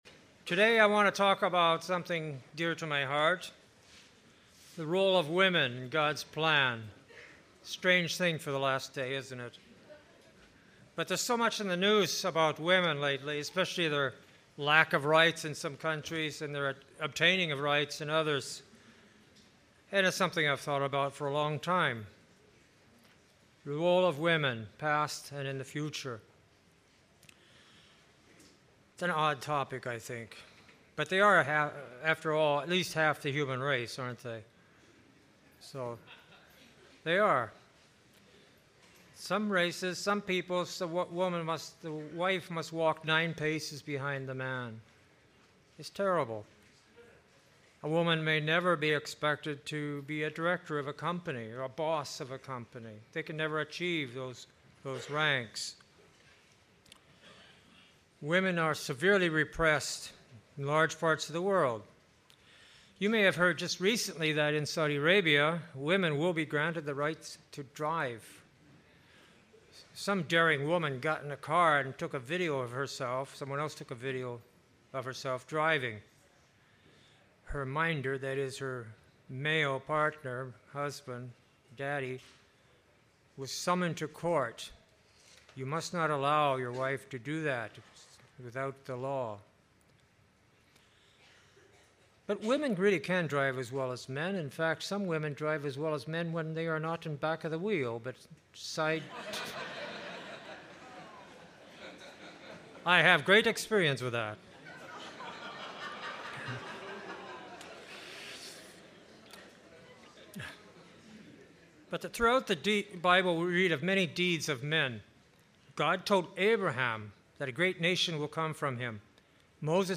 Sermonette